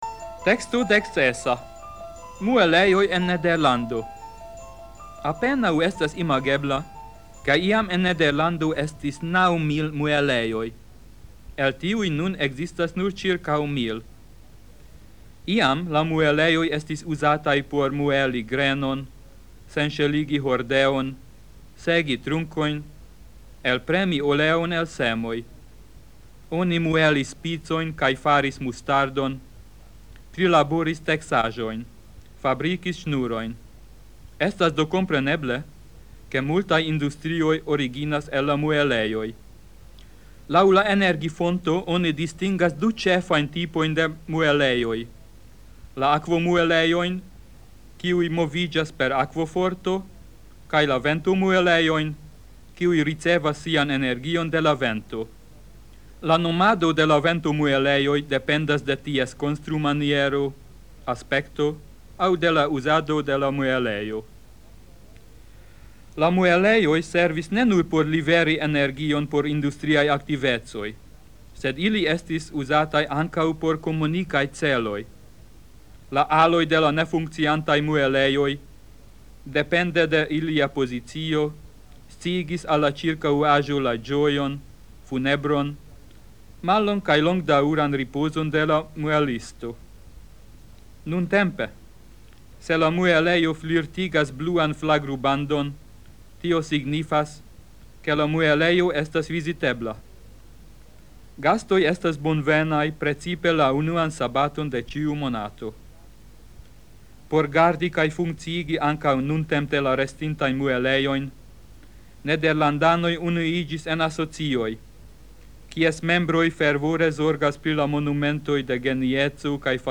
Kategorio: komprena
Temo: Sonmaterialo de E-lingva teksto kun pollingvaj kontrolekzercoj.